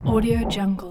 دانلود افکت صدای بوم بوم قلب 2
Sample rate 16-Bit Stereo, 44.1 kHz